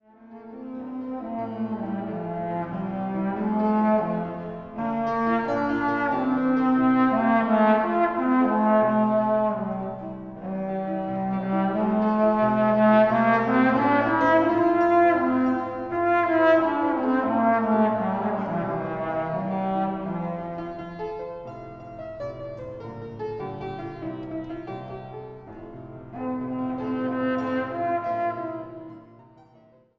Klassische Posaune
Hammerklavier